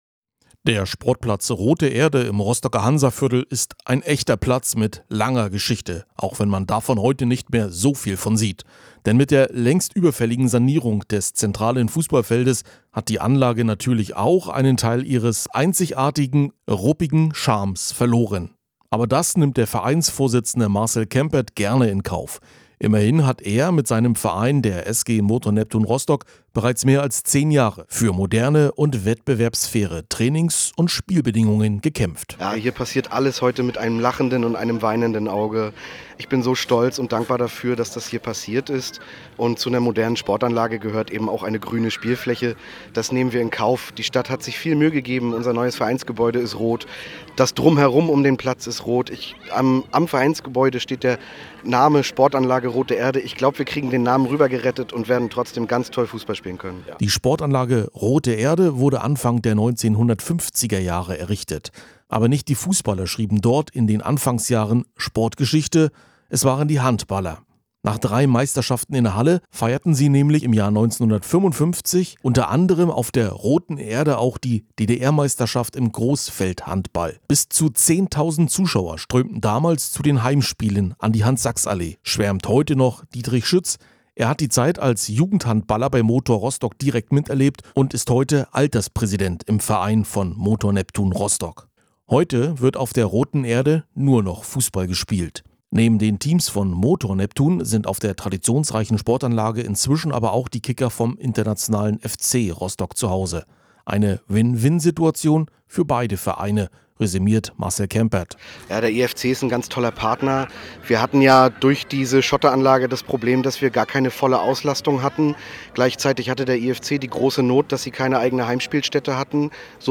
Beitrag zur Übergabe des neuen Kunstrasenplatzes